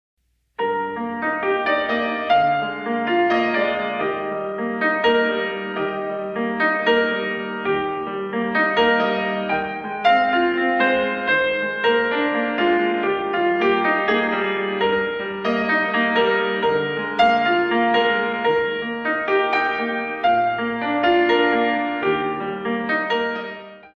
Pianist
In 3